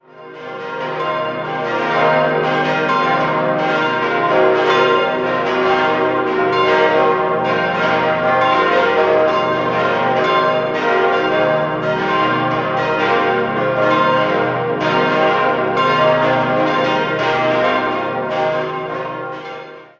6-stimmiges Geläute: c'-es'-f'-g'-b'-c'' Die kleine Glocke wurde im Jahr 2012 von Grassmayr in Innsbruck gegossen. Alle anderen stammen aus der Gießerei St. Florian: die große entstand 1928, die vier mittleren 1956.